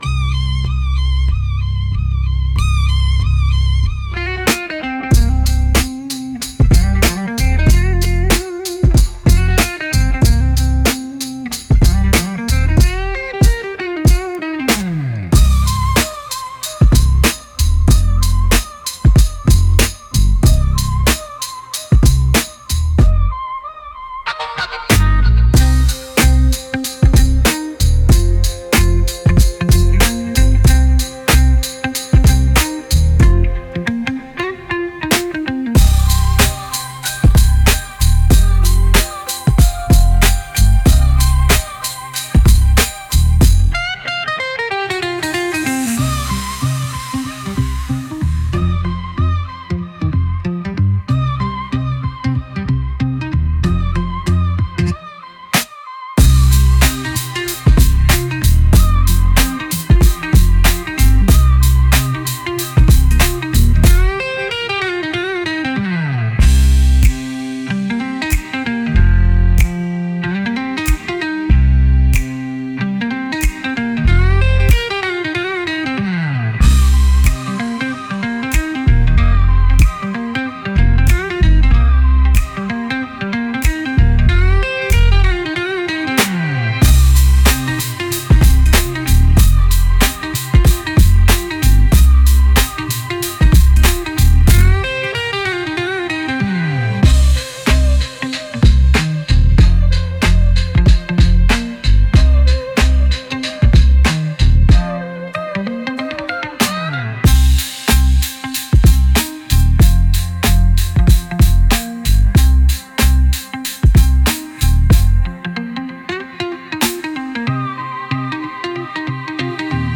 Genre: Hip-Hop Mood: Old School Editor's Choice